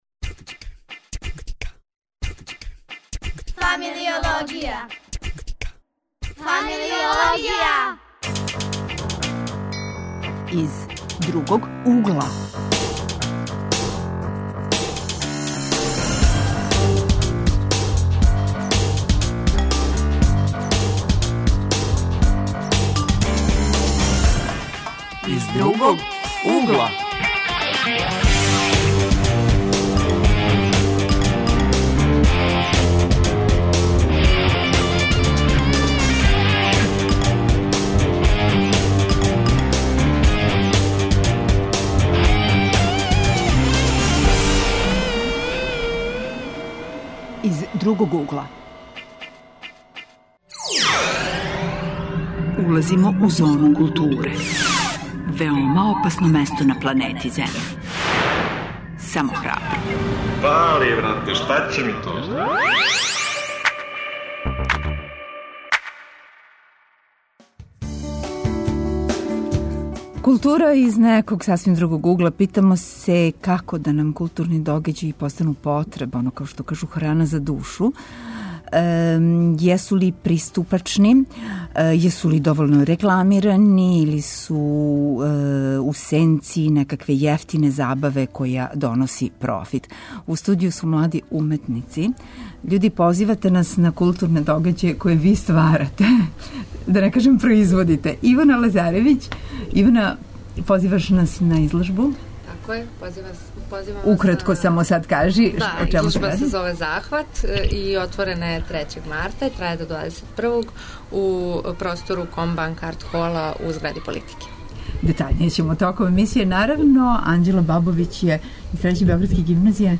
Тема емисије је 'Нека култура буде ИН'. Говорићемо о томе како младима приближити културу. Наши гости су млади уметници.